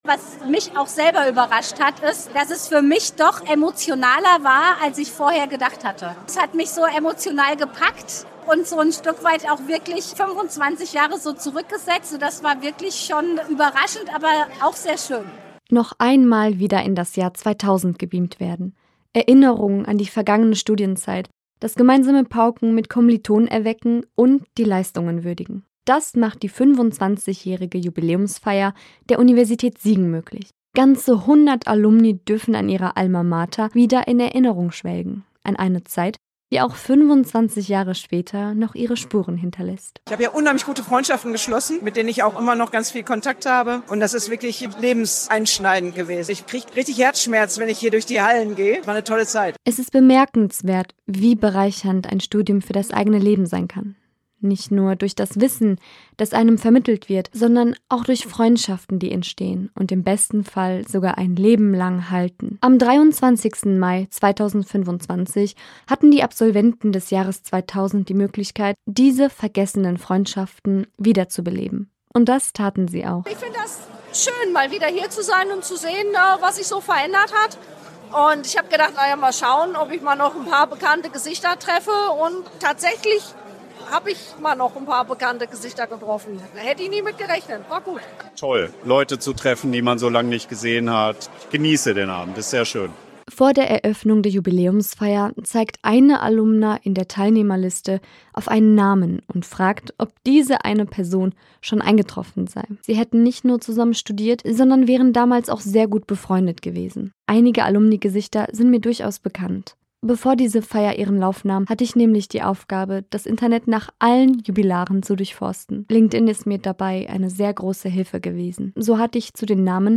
Audioreportage
In dieser Audioreportage berichten Ehemalige, wie es sich anfühlt nach 25 Jahren nochmal die Universität zu besuchen, Kommiliton:innen von früher wiederzusehen und erzählen, wie das Studium ihr Leben bereichert hat.